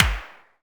clapOnbeat4.ogg